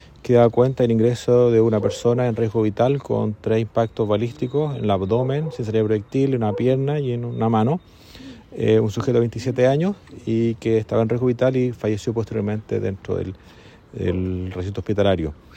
Todo esto lo informó el fiscal ECOH, Eduardo Jeria.